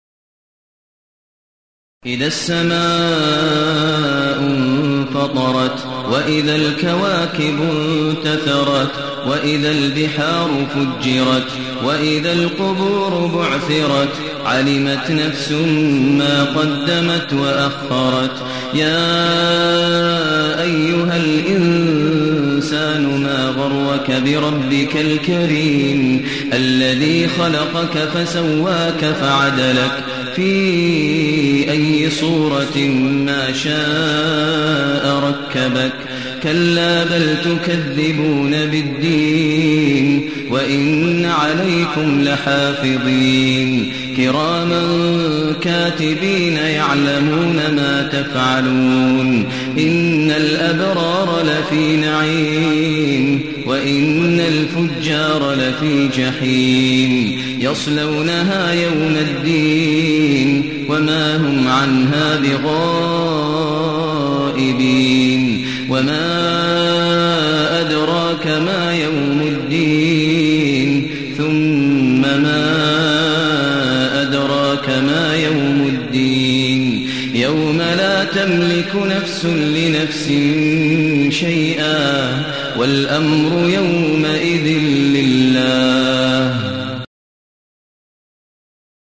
المكان: المسجد النبوي الشيخ: فضيلة الشيخ ماهر المعيقلي فضيلة الشيخ ماهر المعيقلي الانفطار